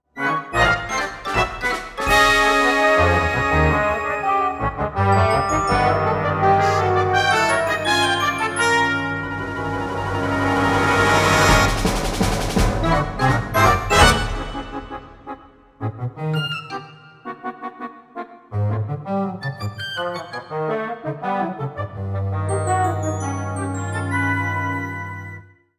This new concert band studio recording